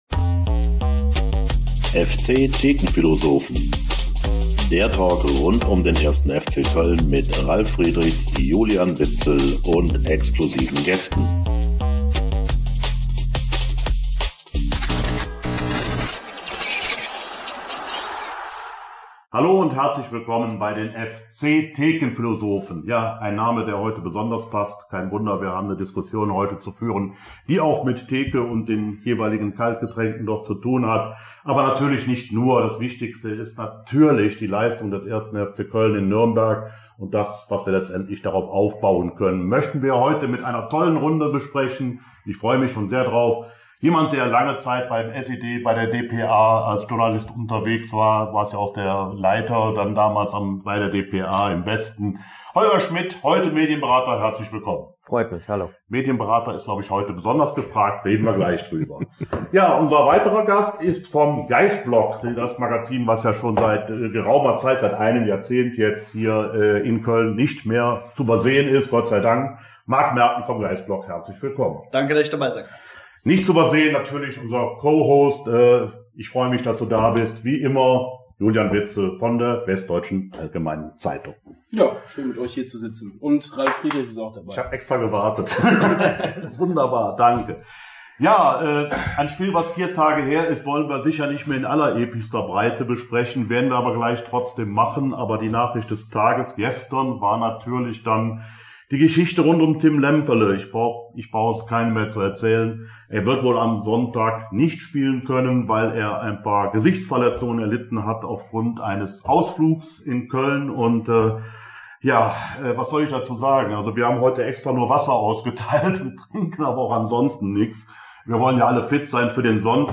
FC Köln vor dem Aufstiegs-Endspiel gegen Kaiserslautern: Lemperle-Schock, Sorgen & neue Euphorie - Folge 95 ~ FC-Thekenphilosophen - Der Talk Podcast